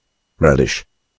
Relish [ˈrelɪʃ] (